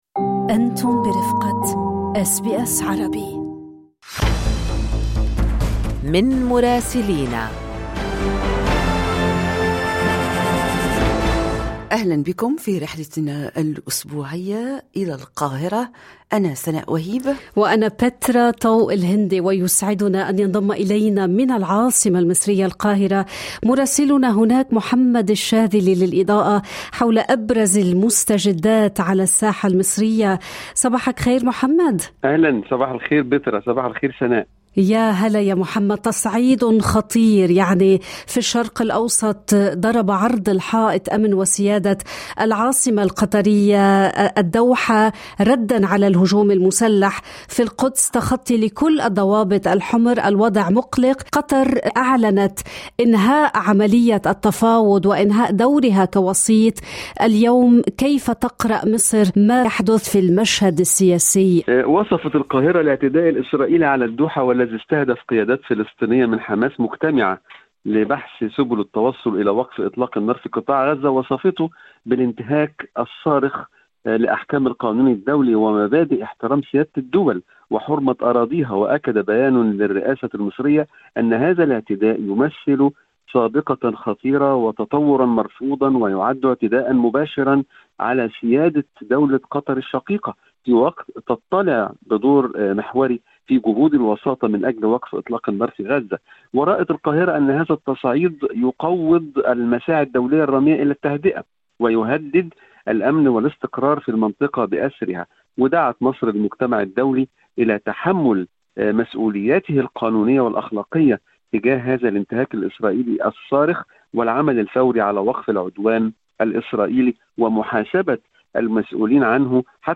رصد مراسل أس بي أس عربي